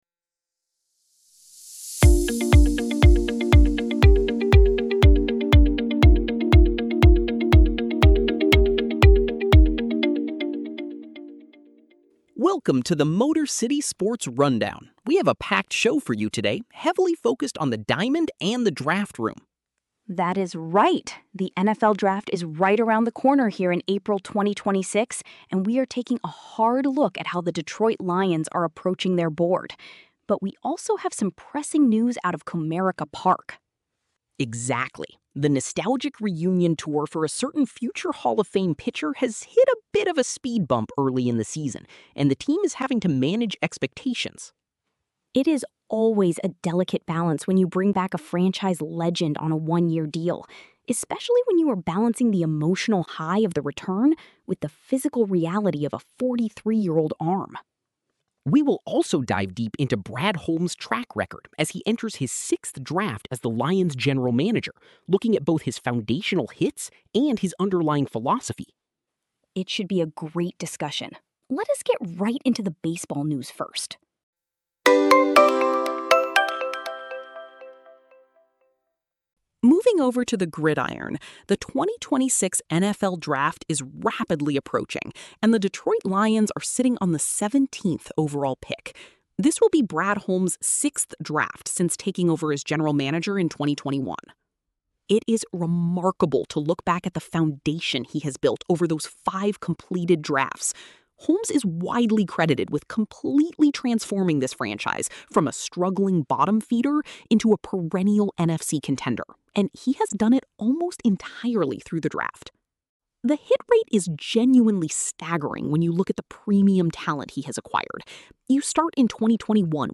AI-hosted daily audio briefings.
Local news + weather for greater Grand Rapids, Michigan. Two AI hosts, fresh every morning.